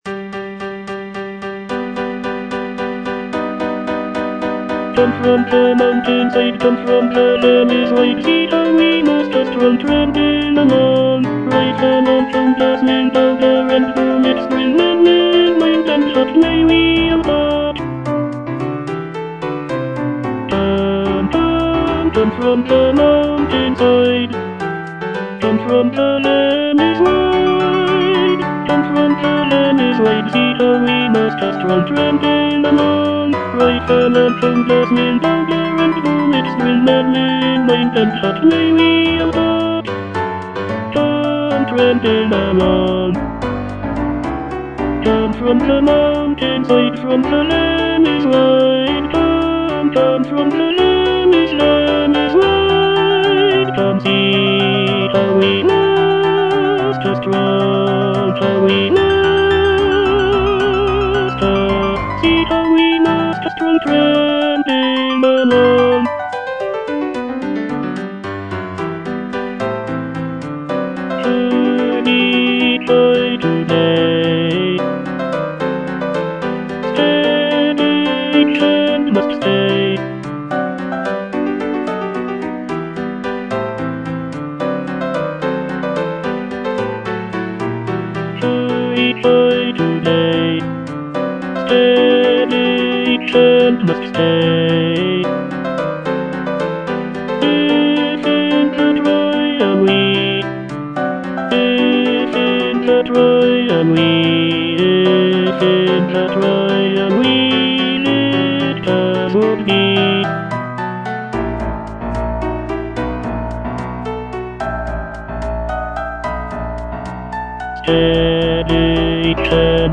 E. ELGAR - FROM THE BAVARIAN HIGHLANDS The marksmen (tenor I) (Voice with metronome) Ads stop: auto-stop